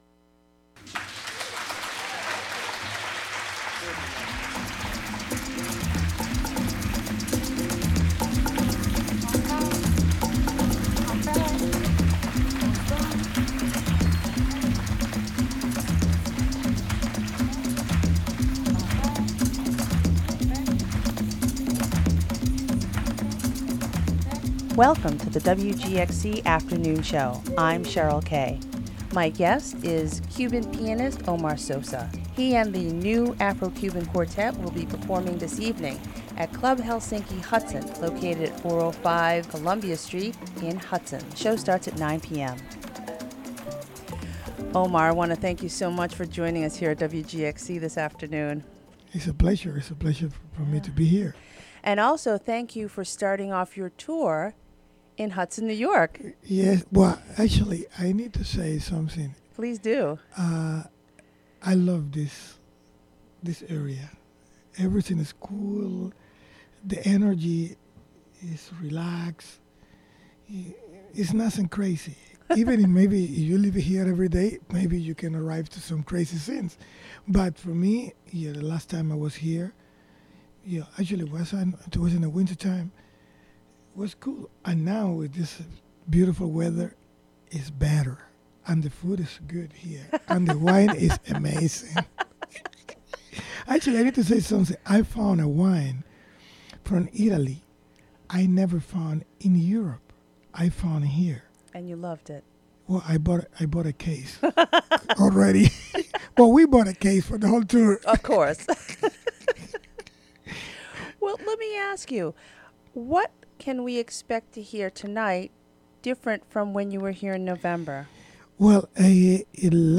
Interviewed
Pianist Omar Sosa discusses his evening performance with his ensemble in Hudson.